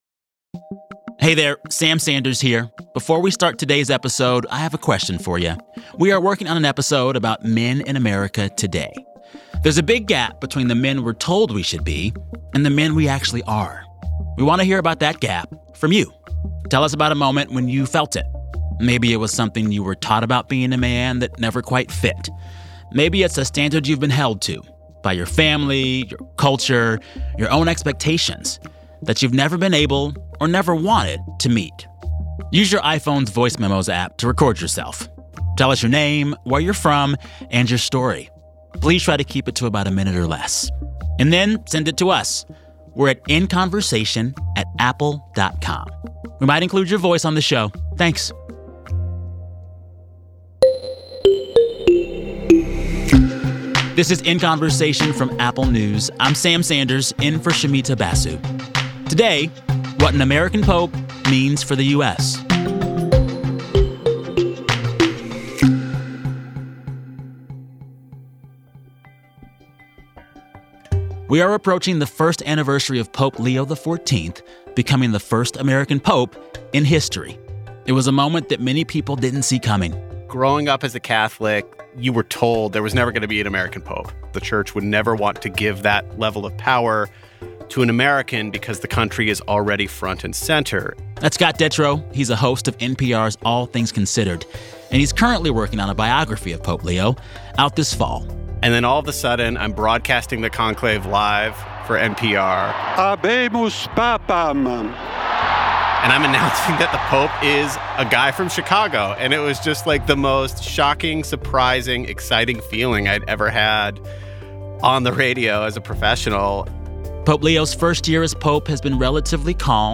Listen to the full interview on Apple Podcasts.